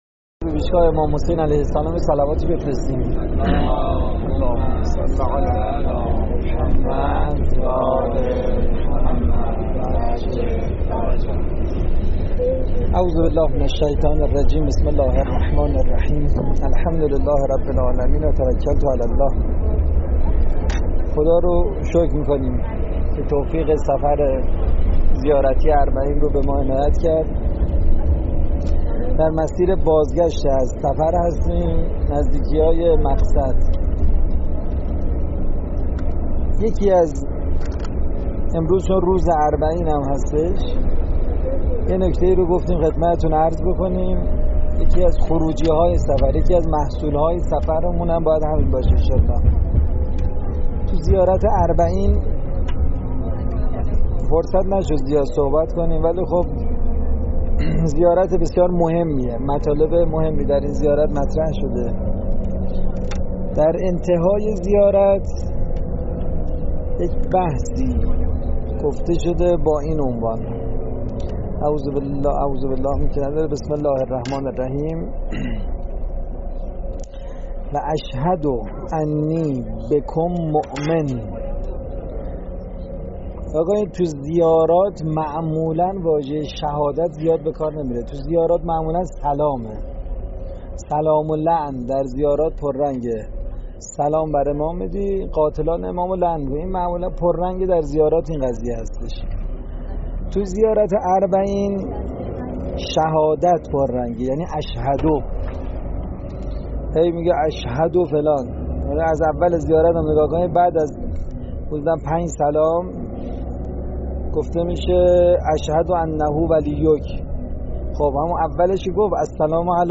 کلاس اربعین